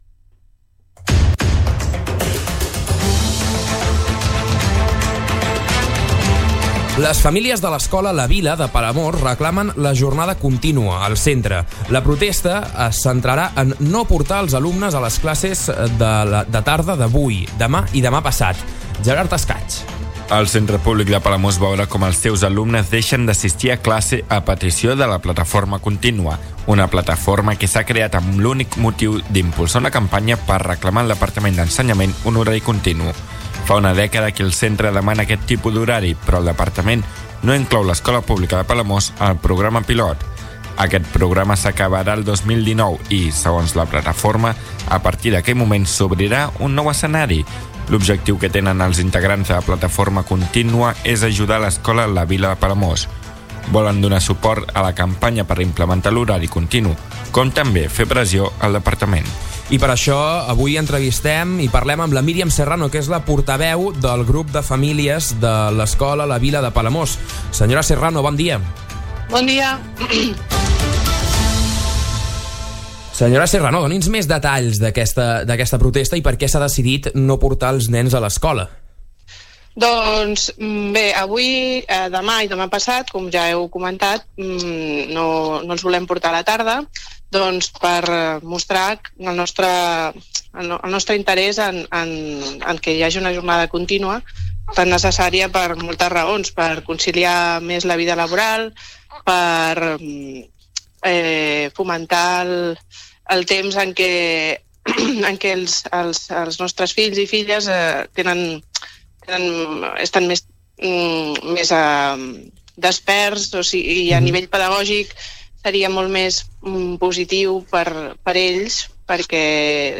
Entrevistes SupermatíPalamós